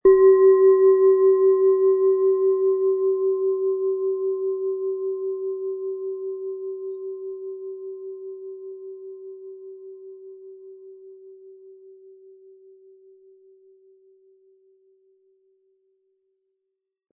Die DNA-Frequenz (528 Hz):
Wie klingt diese tibetische Klangschale mit dem Planetenton DNA?
Diese antike Klangschale ist an der Oberfläche patiniert bzw. mit Altersflecken versehen, was den Klang in keiner Weise beeinträchtigt.